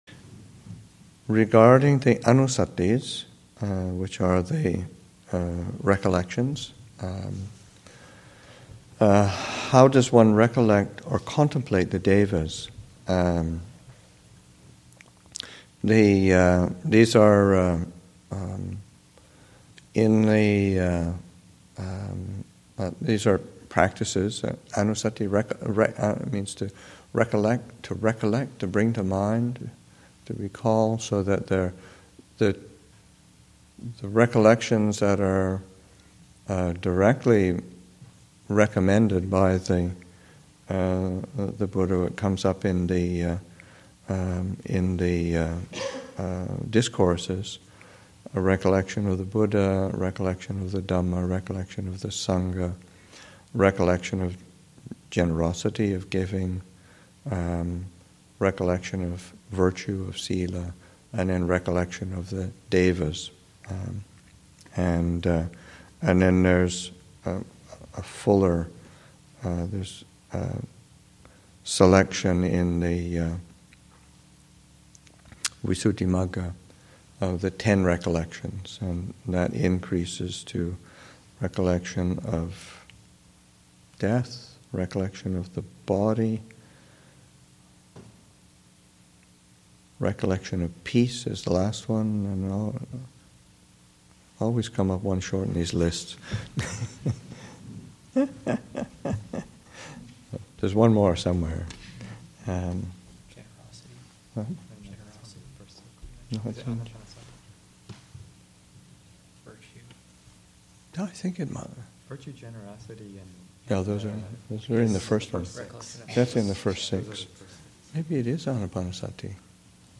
2014 Thanksgiving Monastic Retreat, Session 2 – Nov. 23, 2014